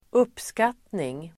Uttal: [²'up:skat:ning]